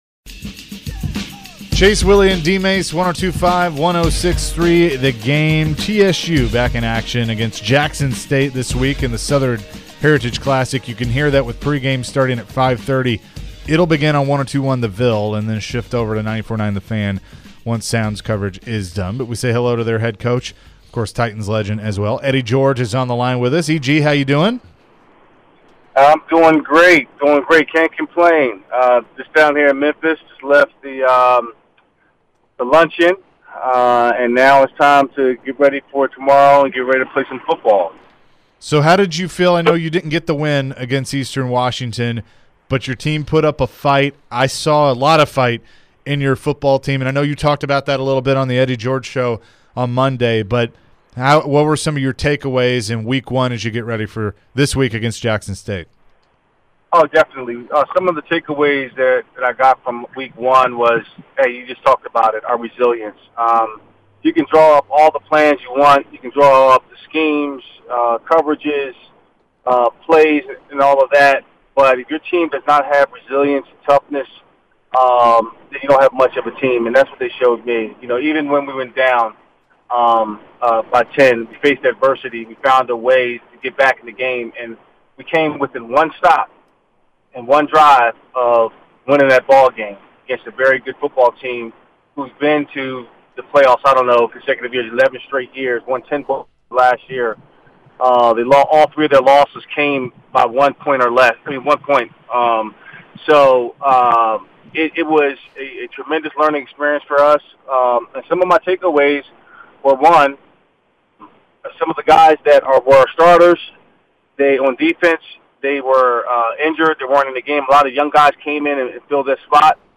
Eddie George interview (9-9-22)